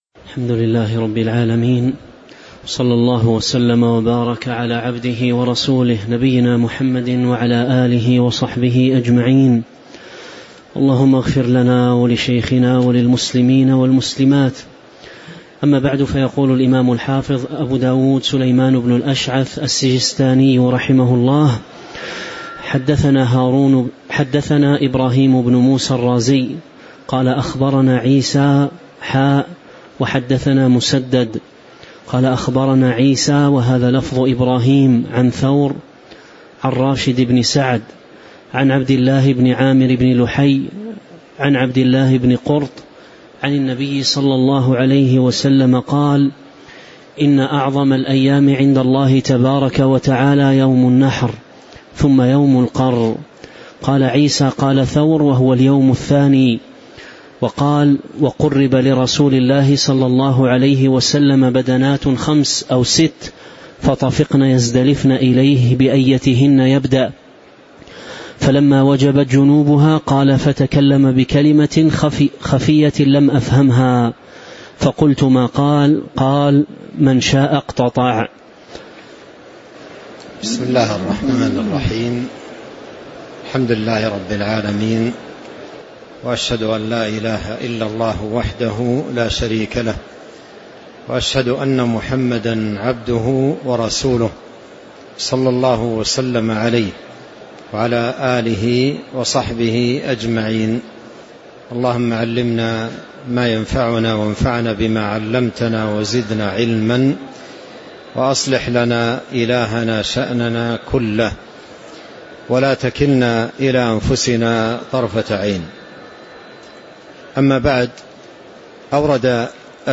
تاريخ النشر ٢٤ ذو القعدة ١٤٤٦ المكان: المسجد النبوي الشيخ